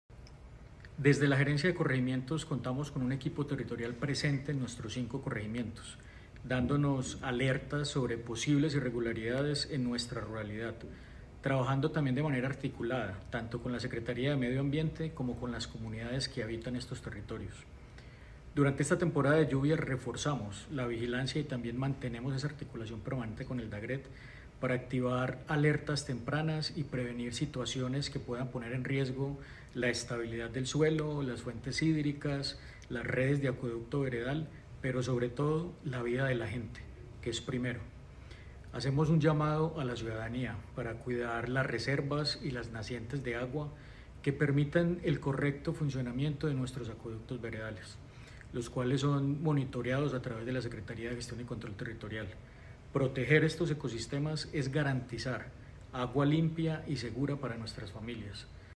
Declaraciones gerente de Corregimientos, Andrés Felipe López Vergara
Declaraciones-gerente-de-Corregimientos-Andres-Felipe-Lopez-Vergara.mp3